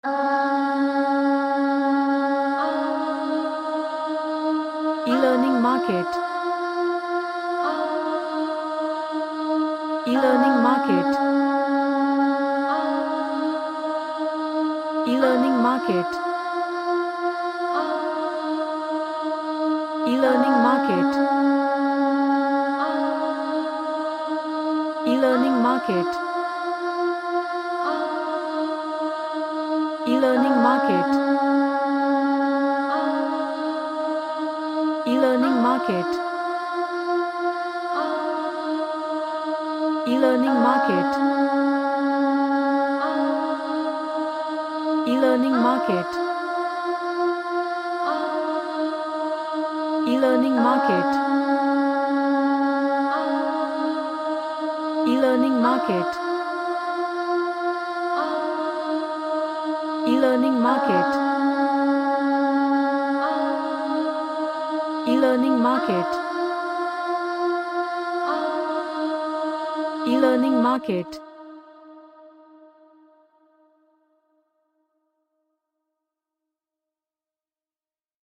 A Reggae track with happy vibes.
Happy